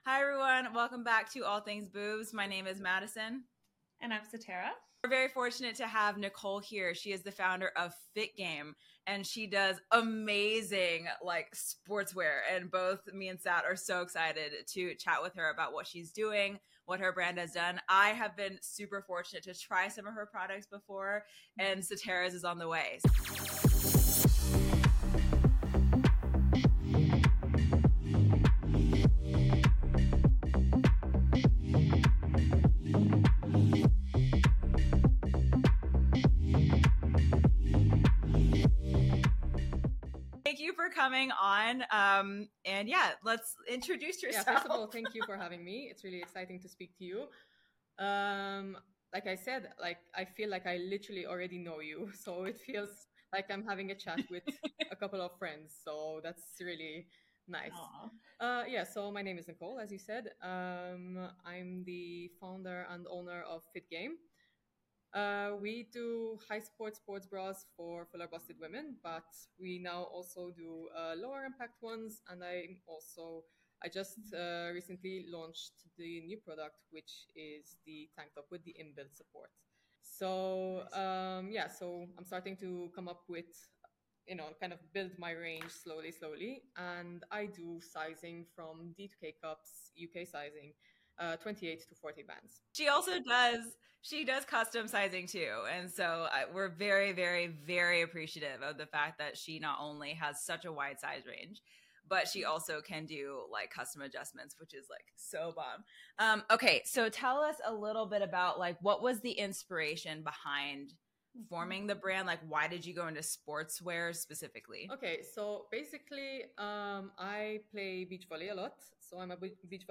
FIT GAME | FULLER BUST SPORTS BRAS | INTERVIEW WITH | ALL THINGS BOOBS PODCAST | PODCAST INTERVIEW | SPORTS BRA INNOVATION | LIKE NEVER SEEN BEFORE |